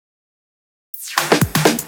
Fill 128 BPM (10).wav